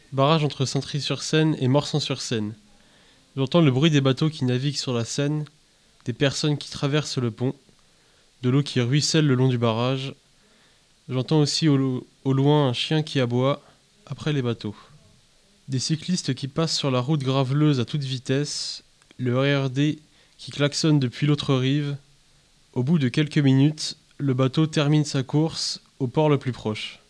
Une minute près du barrage entre Saintry-sur-Seine et Morsang-sur-Seine - Les villes passagères
Un barrage à l'écoute, par un étudiant d'Evry.
une_minute_au_barrage.mp3